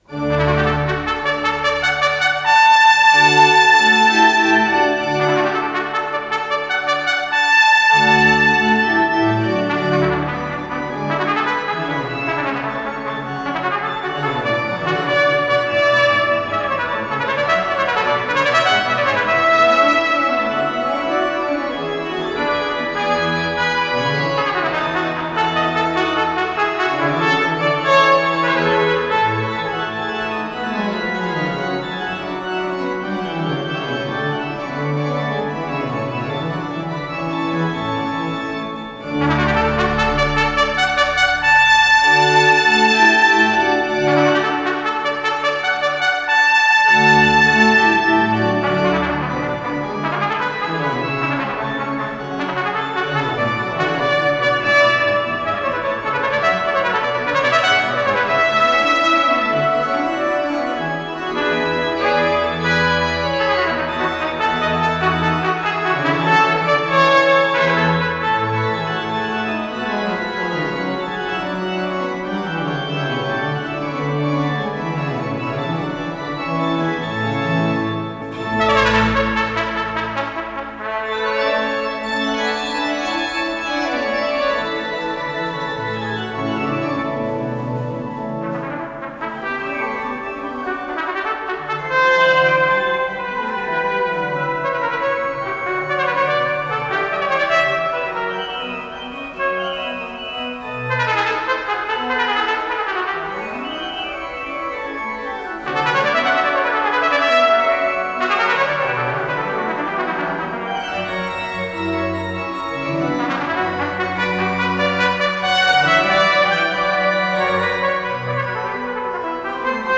с профессиональной магнитной ленты
Авторы версииПереложение - Тимофей Докшицер
ИсполнителиТимофей Докшицер - труба
ВариантДубль моно